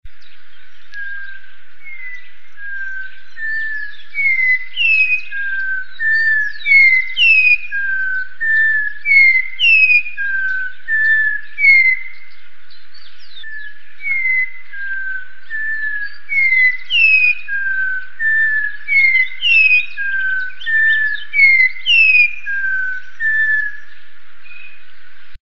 Matico (Icterus croconotus)
Nombre en inglés: Orange-backed Troupial
Fase de la vida: Adulto
Localidad o área protegida: Reserva Ecológica Costanera Sur (RECS)
Condición: Silvestre
Certeza: Vocalización Grabada